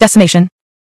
wow-weakauras/WeakAuras/Projects/RaiderSA/out/genericfemale/Decimation.ogg at master